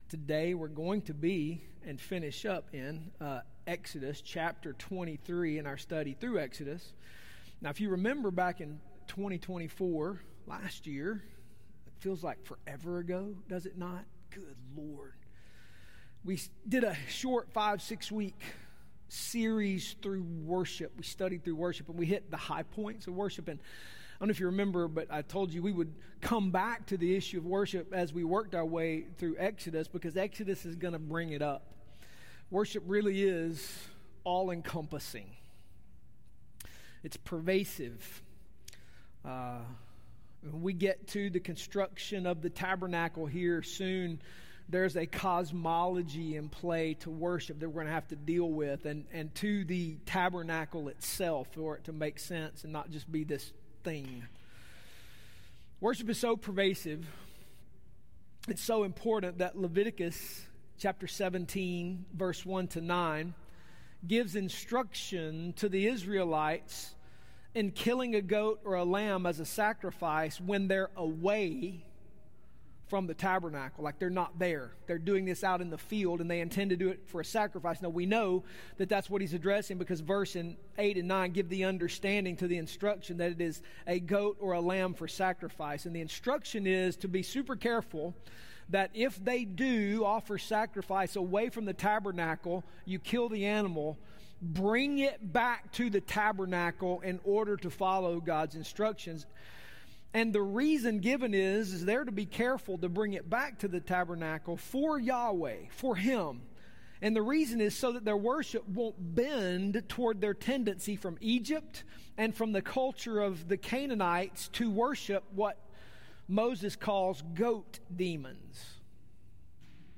Today's sermon talks about the tradition and importanc of Sabbath as it pertains to worship. Other traditions and feasts are discussed and broken down along with booths and tabernacles before a thorough, yet impactful application.